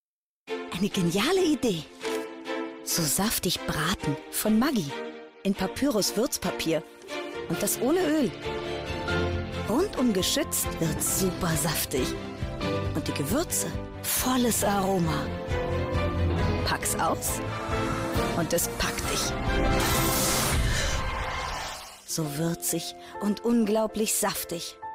Wandelbare, charakteristische Stimme mit einem Stimmalter von ca 30 bis 50 Jahren.
Sprechprobe: Werbung (Muttersprache):
Maggi-Werbung.mp3